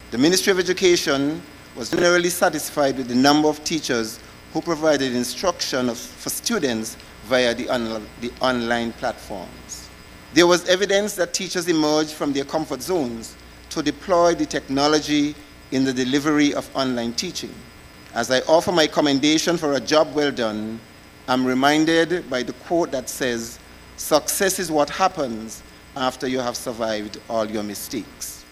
To commemorate the beginning of the new 2021- 2022 school year, an opening ceremony was held in St Kitts on Monday, August 30th.
At the ceremony, Permanent Secretary in the Ministry of Education (St. Kitts), Mr. Vincent Hodge, made these comments commending the teachers for the work in executing the online (or virtual) sessions, during the Covid-19 pandemic: